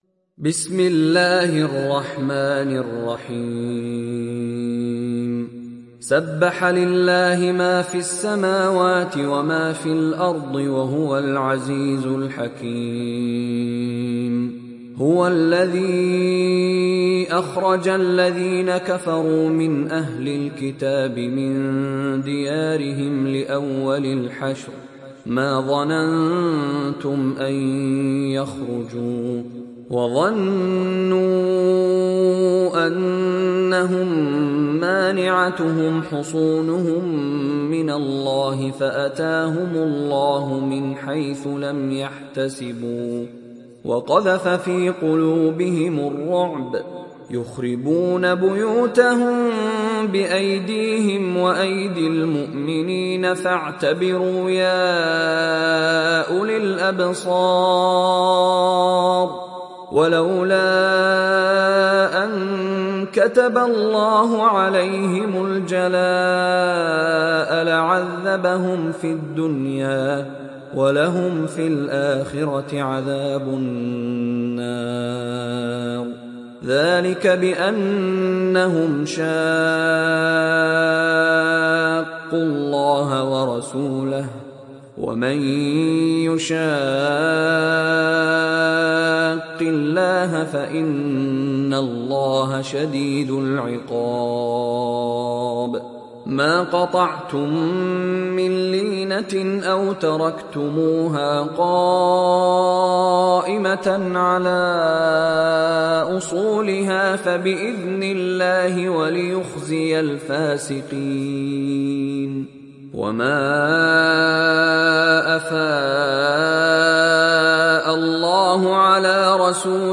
Surah Al Hashr Download mp3 Mishary Rashid Alafasy Riwayat Hafs from Asim, Download Quran and listen mp3 full direct links